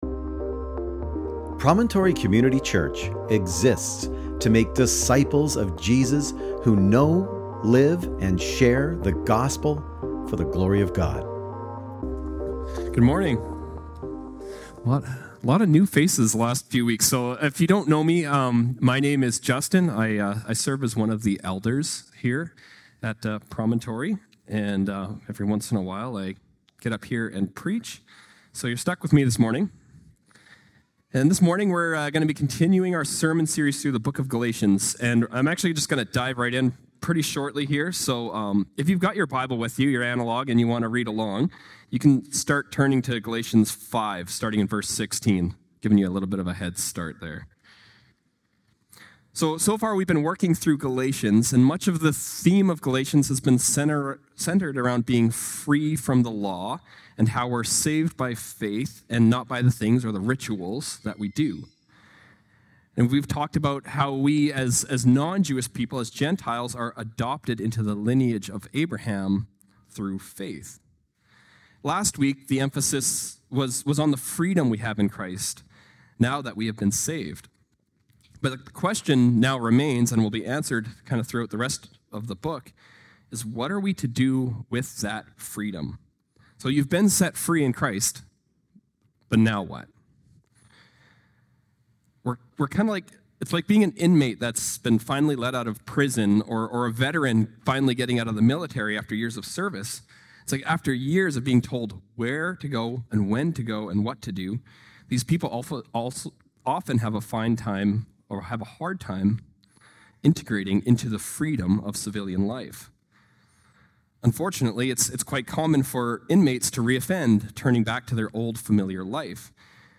Sermon Text: Galatians 5:16-26